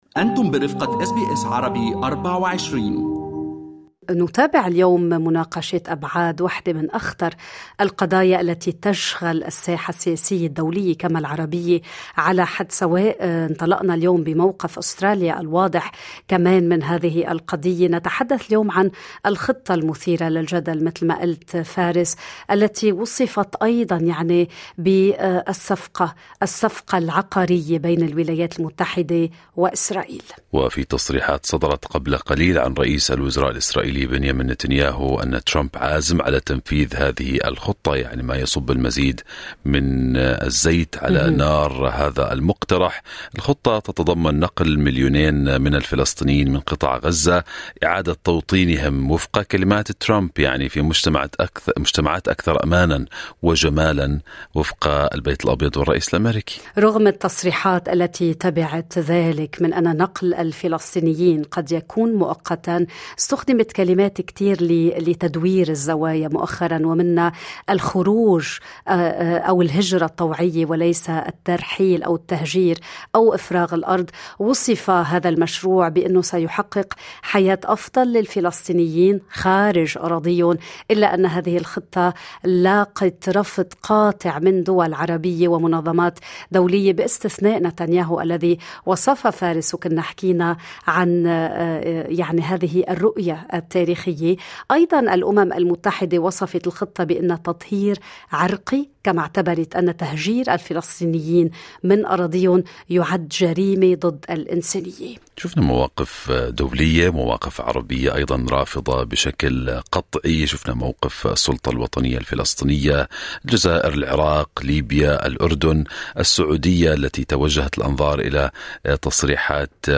في مقابلة مع قناة "فوكس نيوز"، دافع رئيس وزراء إسرائيل بنيامين نتنياهو عن الخطة المثيرة للجدل التي طرحها الرئيس الأمريكي دونالد ترامب، والتي تقضي بطرد الفلسطينيين من قطاع غزة. نحلل هذه التصريحات مع السفير الفلسطني السابق في كانبرا د. عزت عبد الهادي.